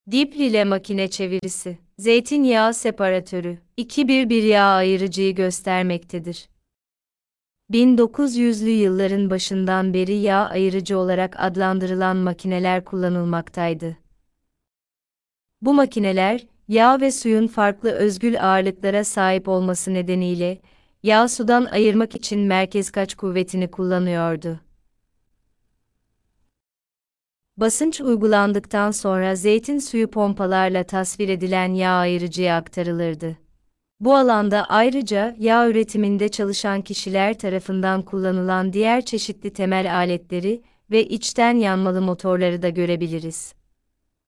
Sesli rehberli tur